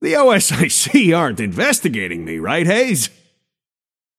Shopkeeper voice line - The OSIC aren’t investigating me, right Haze?